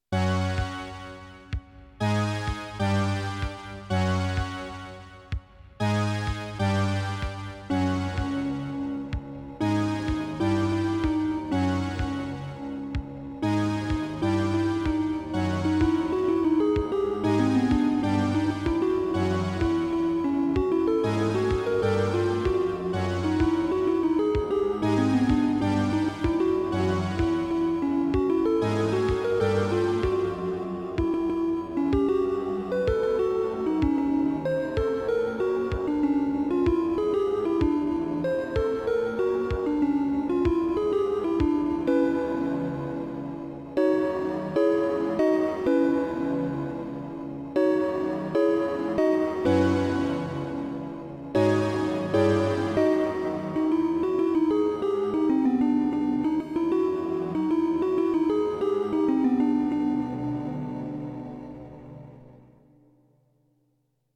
I'll just upload all my PixiTracker songs at once, why not.
Another attempt at a song, also dungeon synth-y!